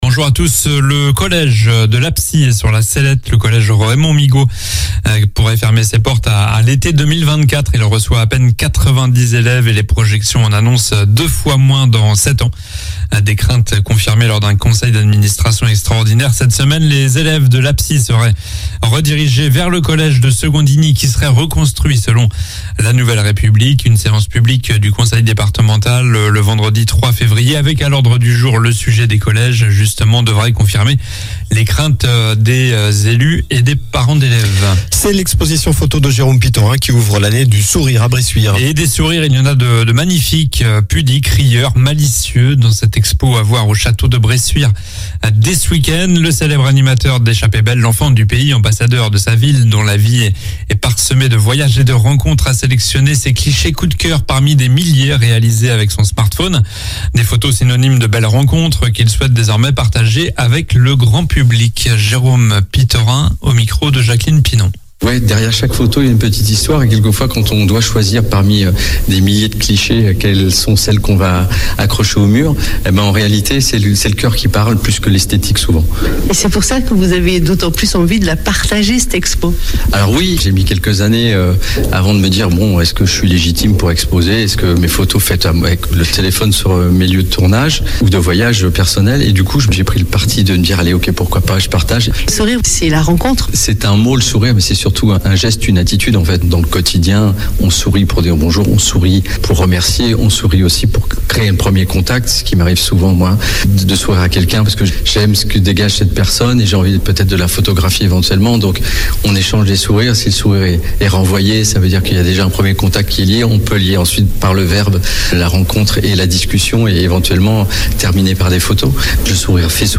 Journal du samedi 21 janvier (midi)